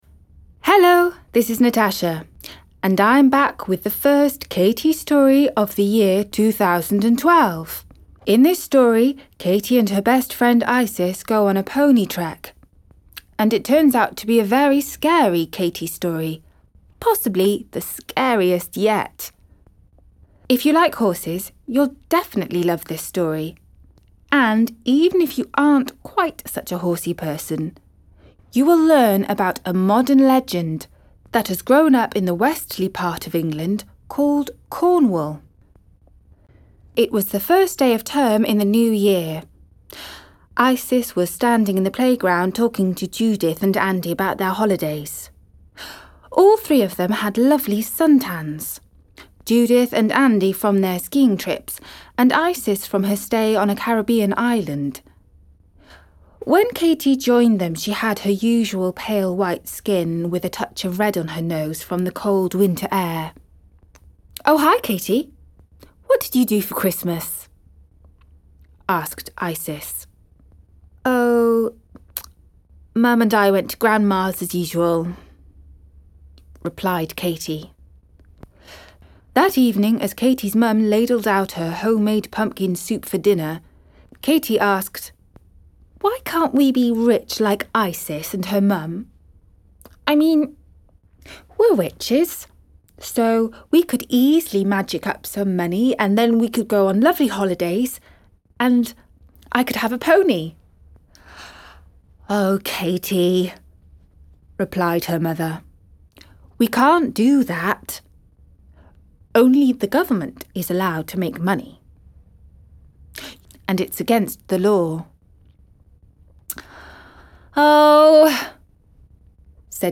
Álbum de Audio Books em Inglês